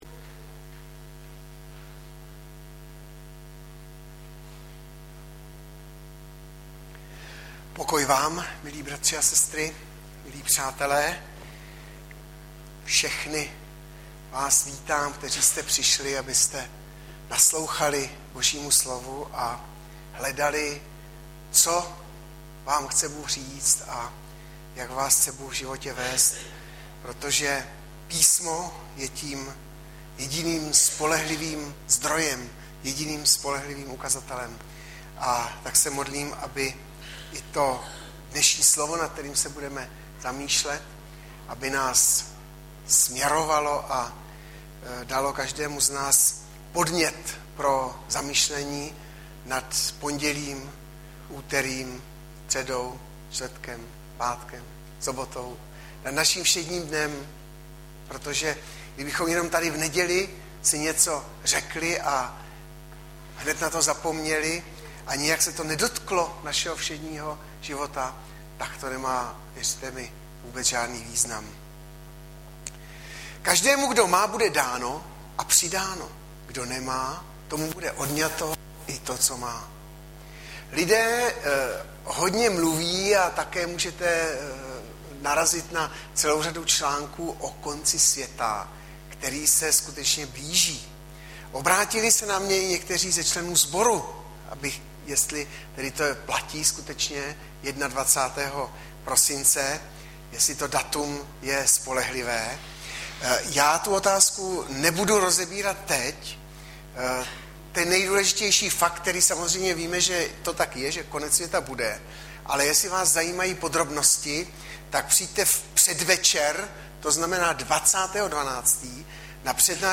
Audiozáznam kázání